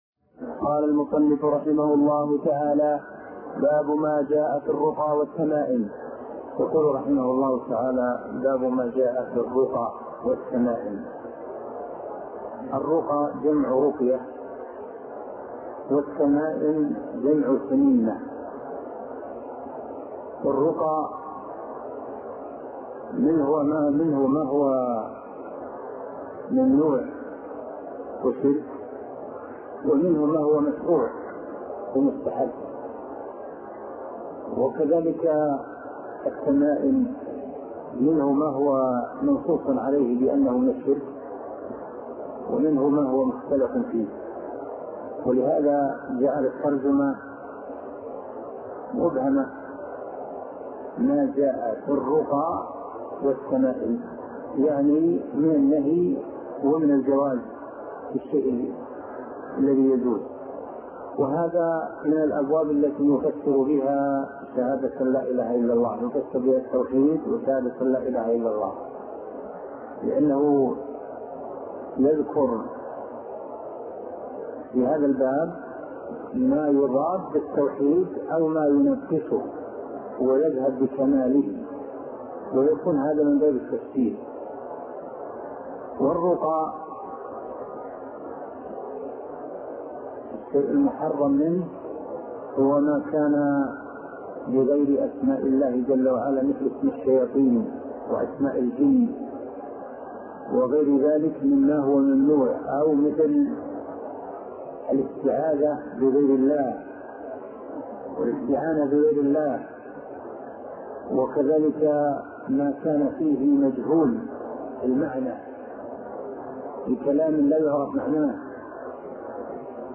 الدرس ( 37) شرح فتح المجيد شرح كتاب التوحيد - الشيخ عبد الله بن محمد الغنيمان